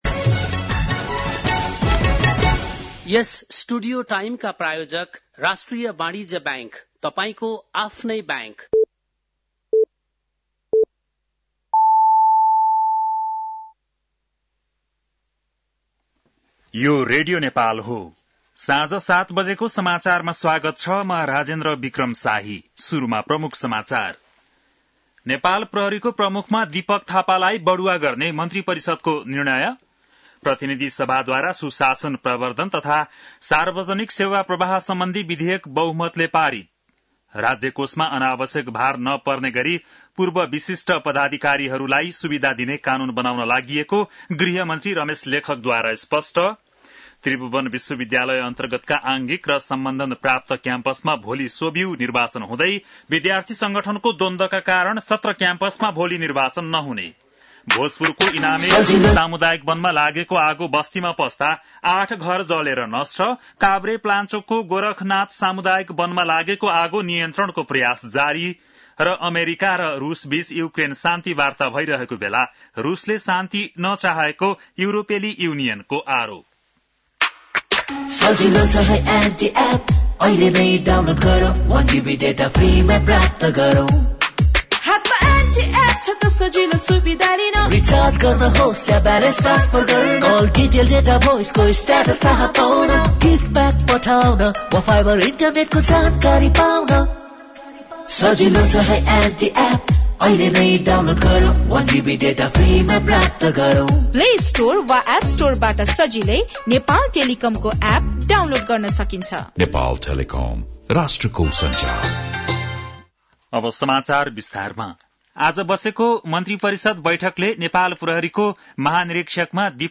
बेलुकी ७ बजेको नेपाली समाचार : ४ चैत , २०८१
7-pm-news-4.mp3